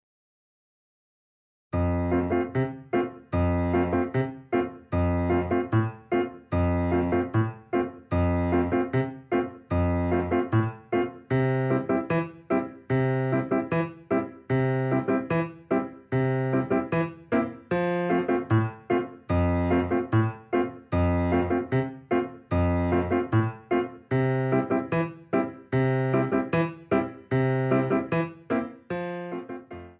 Besetzung: Violine